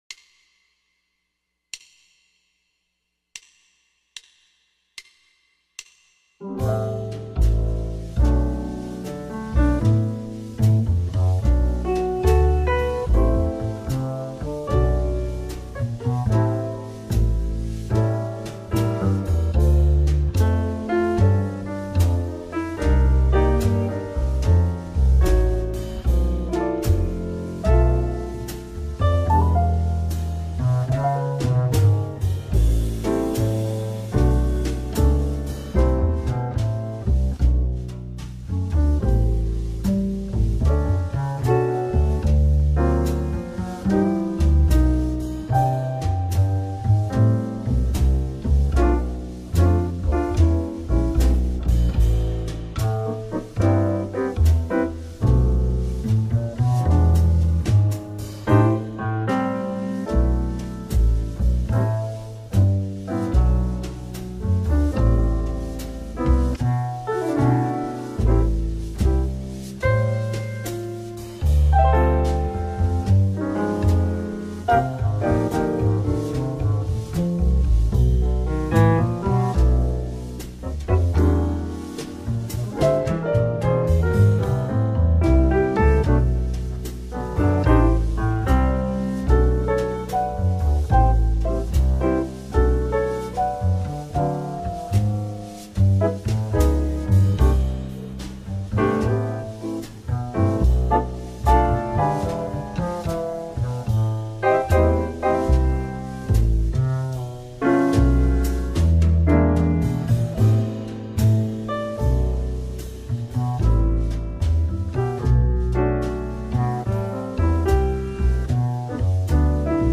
75bpm
in Amin